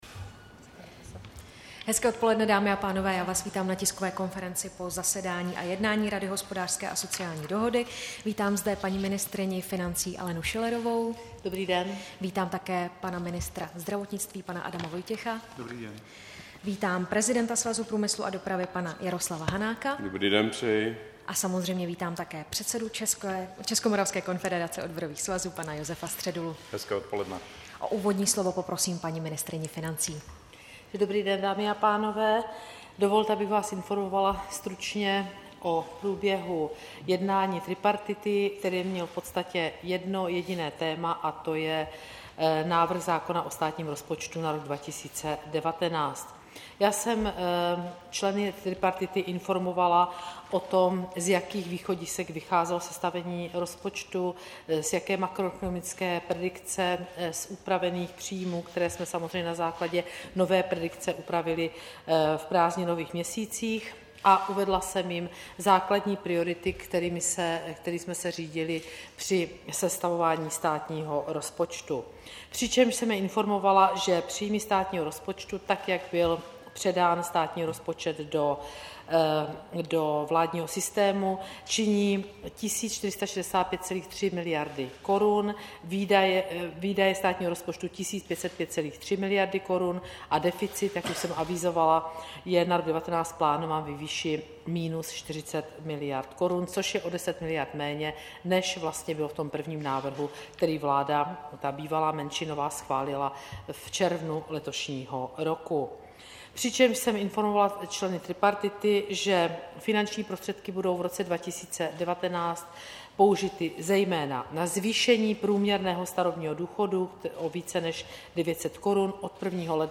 Tisková konference po jednání tripartity, 14. září 2018